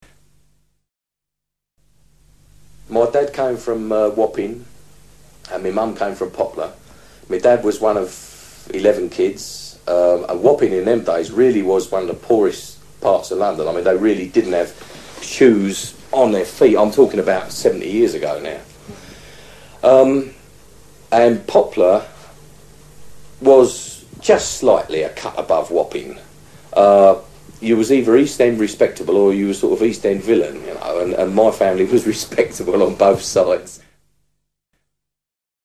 A Cockney Accent
A sample of urban Cockney accent
1) El habla de este orador es de estilo coloquial e incluye el uso de palabras como kids, y la omisión de las consonantes finales: Wappin' (Wapping), an' (and), talkin' (talking).
Generalmente, en el acento cockney se pueden escuchar las siguientes diferencias respecto del acento inglés estándar:
2) Al final de una palabra, se suele emplear una "detención de la glotis" en lugar de pronunciar la letra t:
LONDON-Cockney.mp3